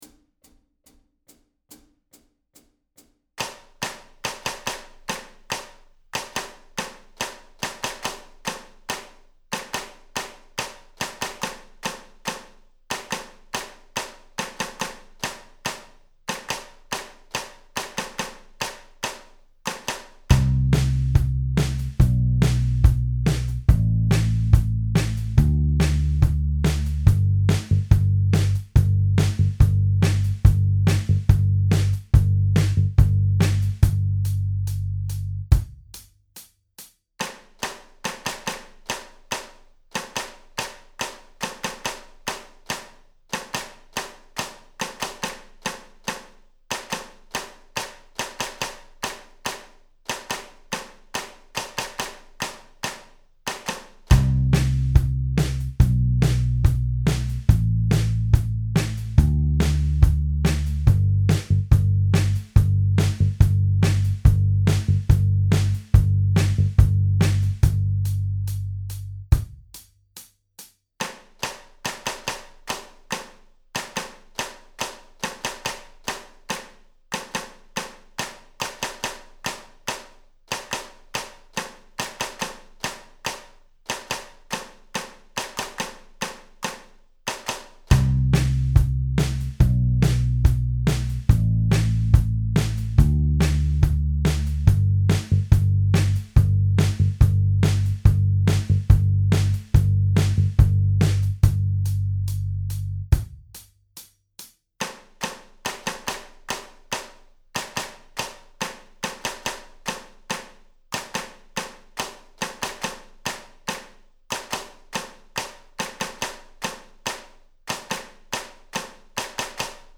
Audio Practice Tracks
Each practice track has a 2 measure count-in, and then the clapping percussion begins.
Full Speed (142bpm) - download, or press the play button below to stream: